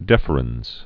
(dĕfər-ənz, -ə-rĕnz)